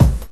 Clean Bass Drum Sample B Key 03.wav
Royality free kick sample tuned to the B note. Loudest frequency: 380Hz
clean-bass-drum-sample-b-key-03-sy2.ogg